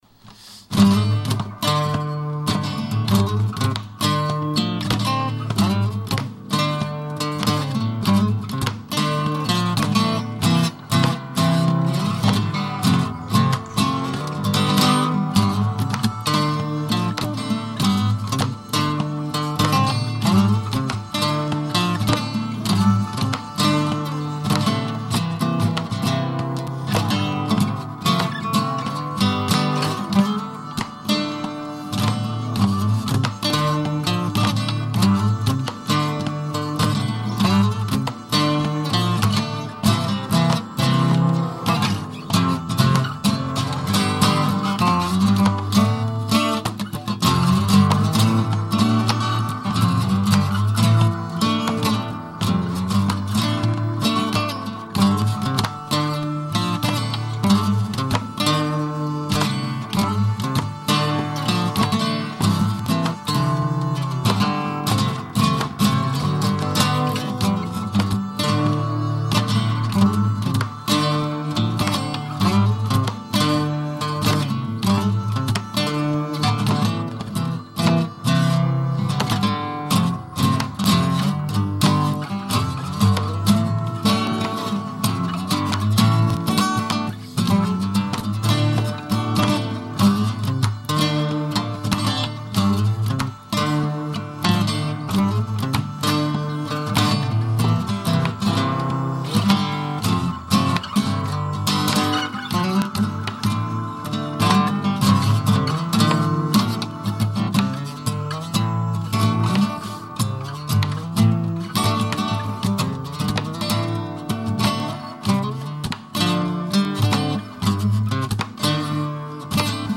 * Create * instrumental music (audio)
Original Music